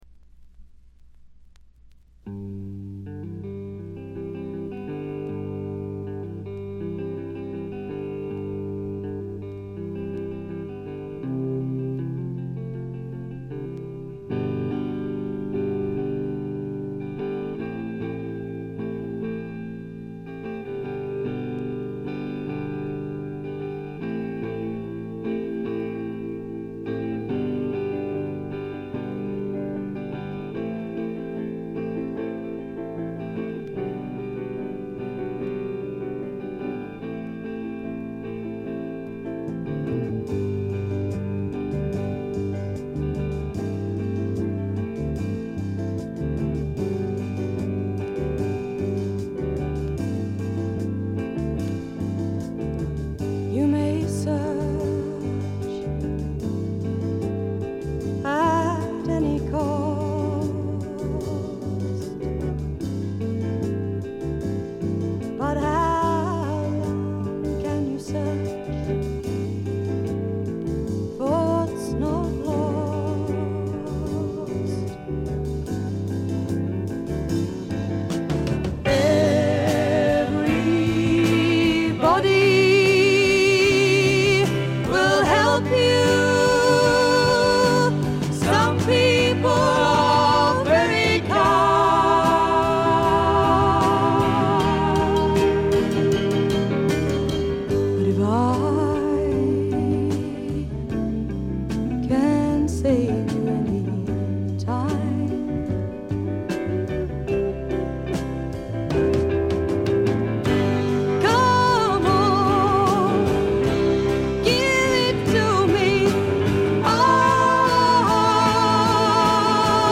極めて良好に鑑賞できます。
英国フォークロック基本中の基本。
試聴曲は現品からの取り込み音源です。